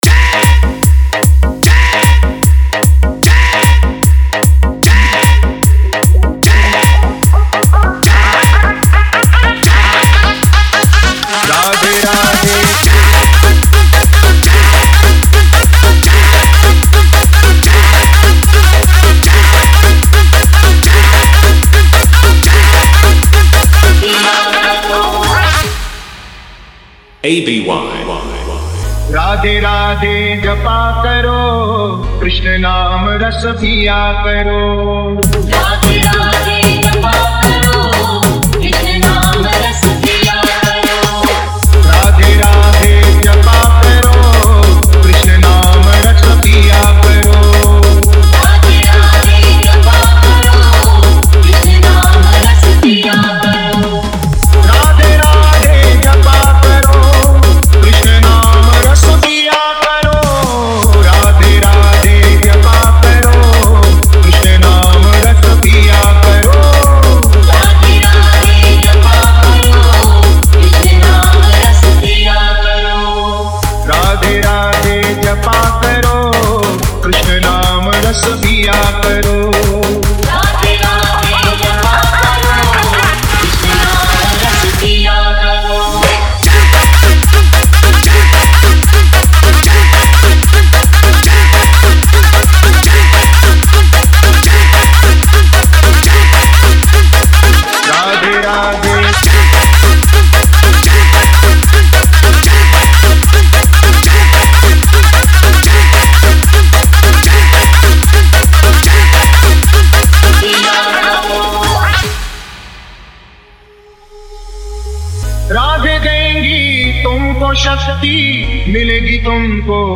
Bhakti Remix Song, Competition Dj Beat
Radhe Radhe Bhajan Remix